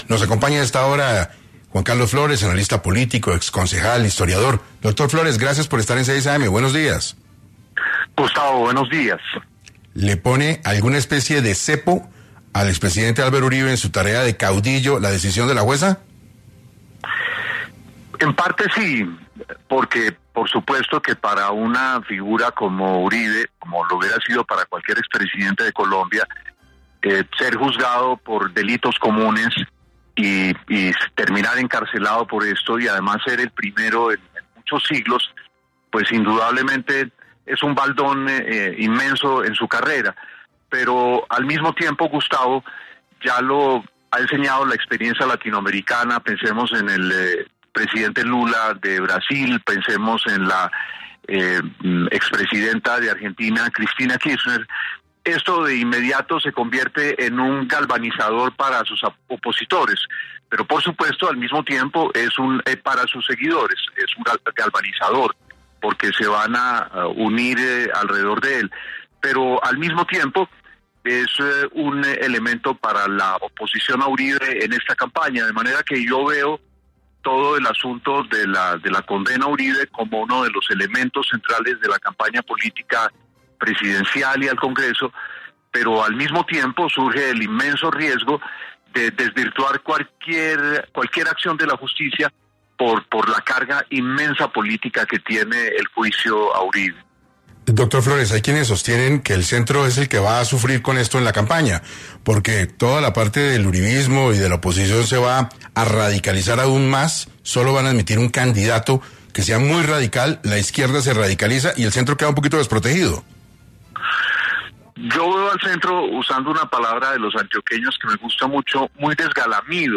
Flórez, analista, exconcejal e historiador, estuvo en 6AM para hablar del impacto político del juicio del expresidente.
En este contexto, Juan Carlos Flórez, analista político, exconcejal e historiador, pasó por 6AM para analizar esta situación y el impacto que tendrá en las elecciones del 2026.